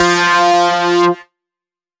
Index of /99Sounds Music Loops/Instrument Oneshots/Leads